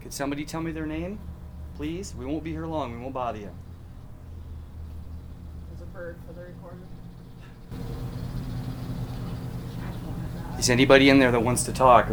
Cemetery Sessions -Digital Voice Recorder Clip 2
I had enough room to place the recorder through a crack in door to record inside the building while shielded from the wind. An amazing EVP is at the very end of the clip!
This voice was clearly from inside the shed, and is unexplained. (Slight noise reduction and amplification have been applied.) Unexplained voice in shed I'm hearing what sounds like a female declaring, "I don't wanna die!". show/hide spoiler Return to Cemetery Sessions Page Return to Investigation Evidence Page